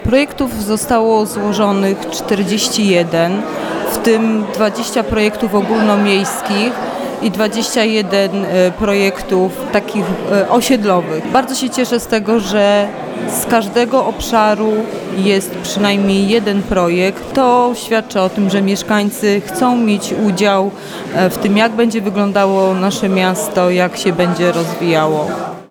Mówi wiceprezydent Mielca, Adriana Miłoś.